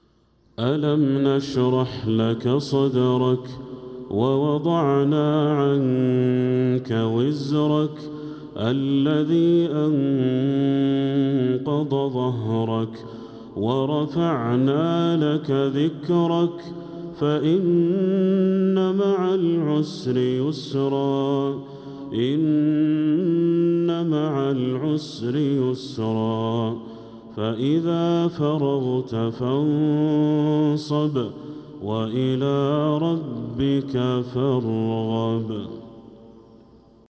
من الحرم المكي 🕋